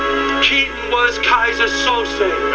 Kose - (Chazz From Usual Suspects, Keaton was Keyser speech) 51.1KB